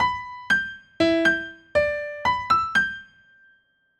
The example above will create a two measure long pattern in 3/4 using quarter notes and eighth notes. The start note or tone, is "D4" and the musical mode is "dorian".
This is what it sounds like when played with a piano (click link to play audio):